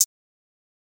Closed Hats
Hi-Hat 3 [ drill ].wav